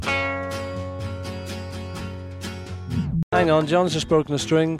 February 26, 1964 unknown takes
bongos